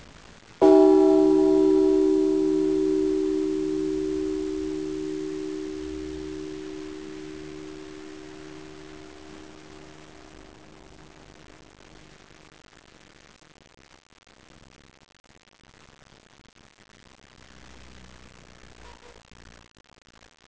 Die folgenden Beispiele gehören zum Geläut der 1641 erbauten Holzkirche in Clausthal.
Abb. 04: Clausthaler Marktkirche, mittlere Glocke, Bronze, 1693,